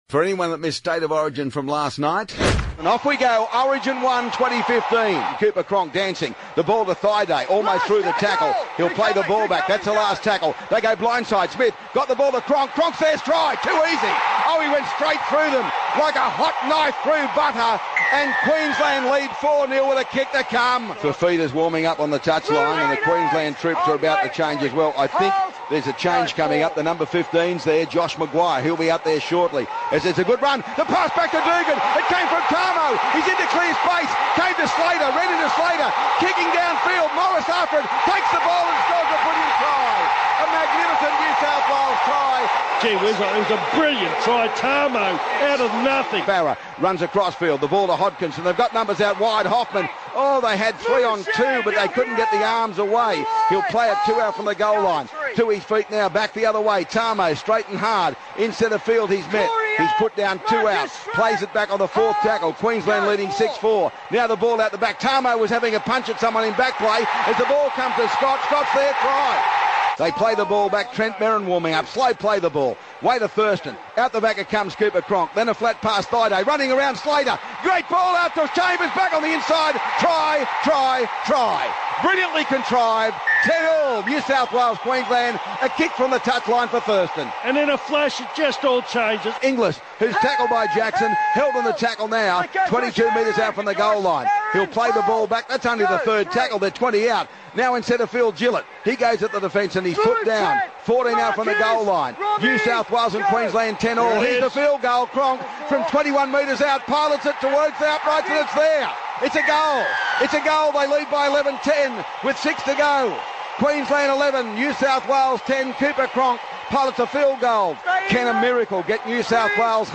Ray plays highlights of his call from last night’s State of Origin game